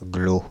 Glos (French pronunciation: [ɡlo]